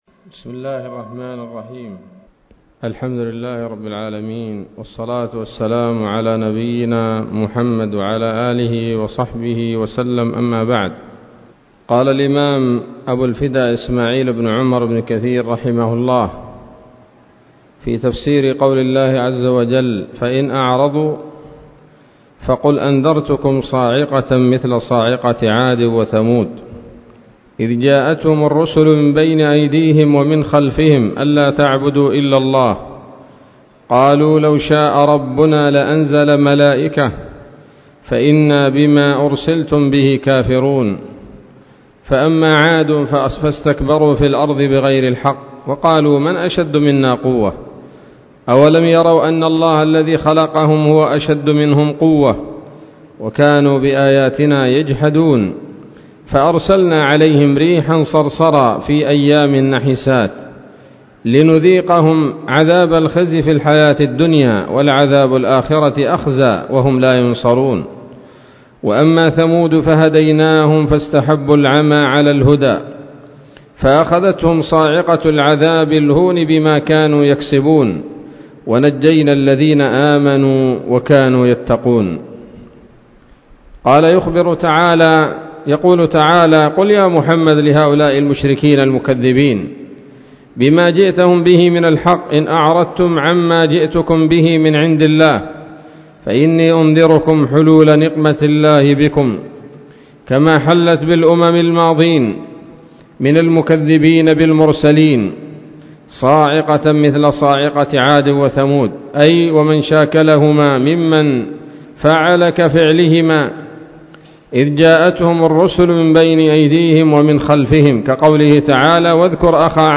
الدرس الرابع من سورة فصلت من تفسير ابن كثير رحمه الله تعالى